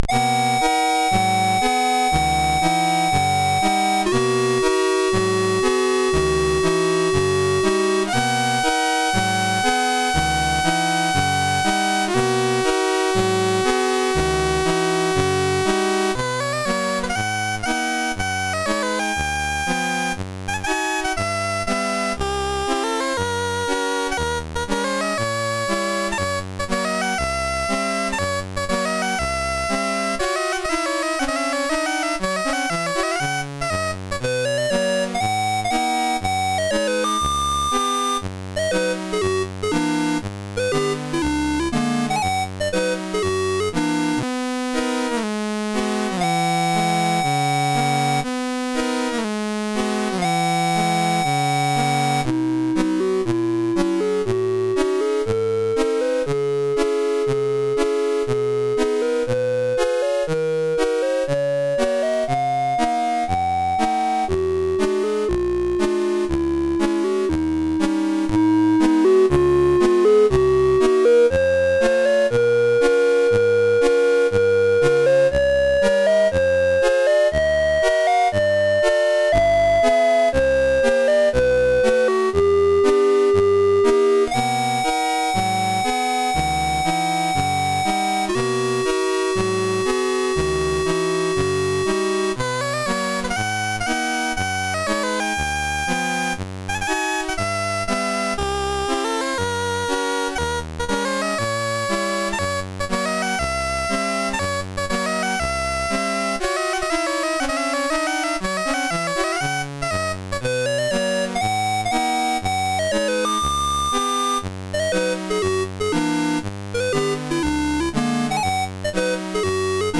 home *** CD-ROM | disk | FTP | other *** search / Loadstar 165 / 165.d81 / promenade.mus ( .mp3 ) < prev next > Commodore SID Music File | 2022-08-26 | 2KB | 1 channel | 44,100 sample rate | 3 minutes